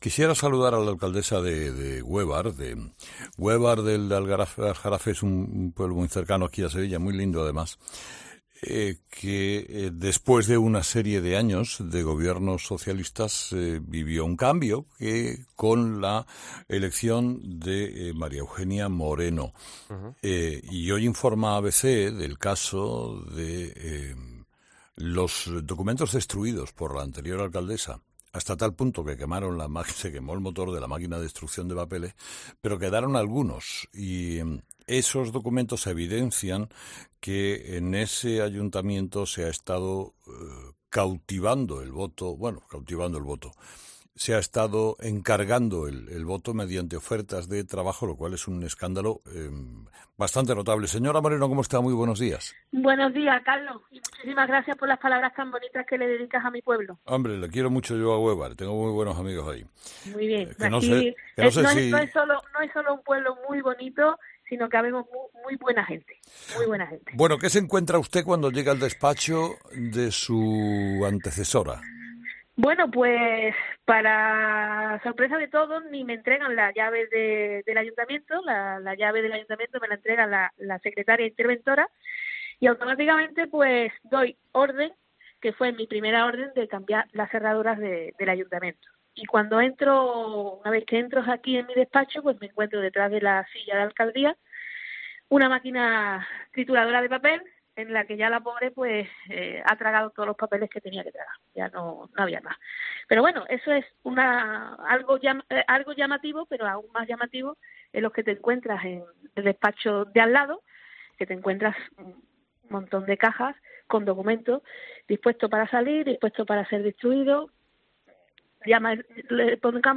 Entrevista a María Eugenia Moreno